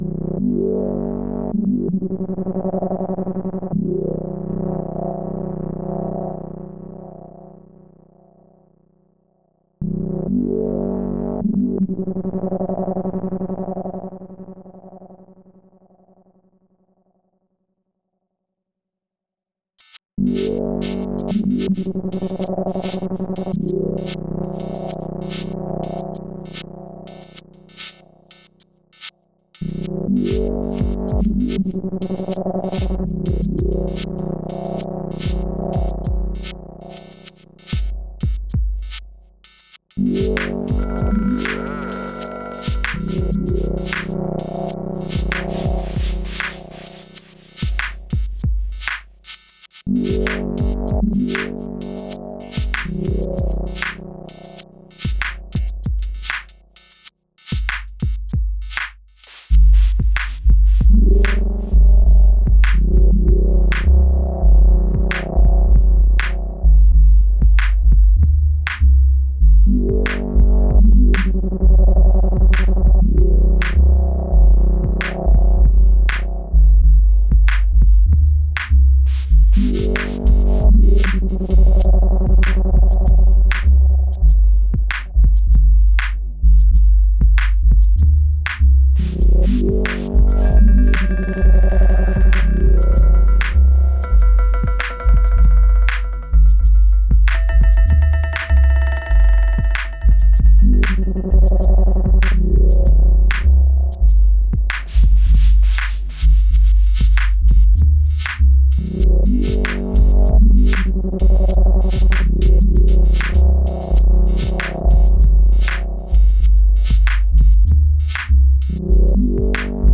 Just the factory samples - the melody is the Tom
This digitakt thing is great!!!